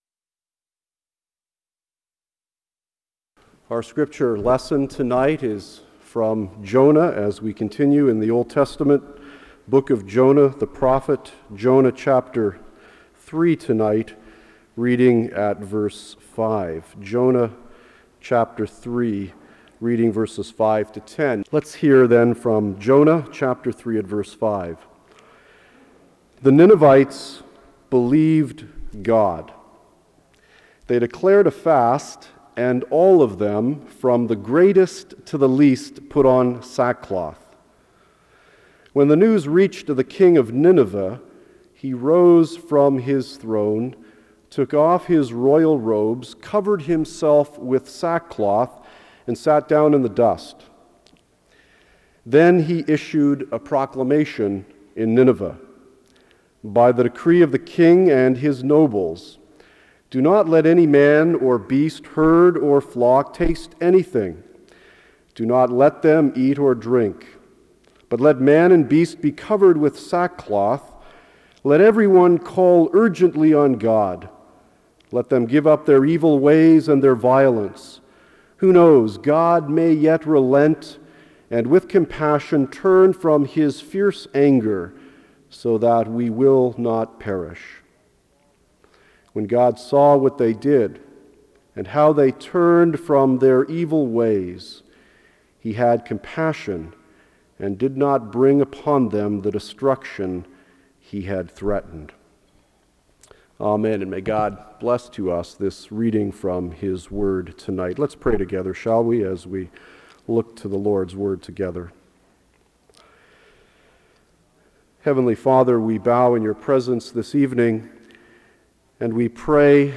The sermon concludes with a reminder of God's compassion and the importance of turning to Him for healing and transformation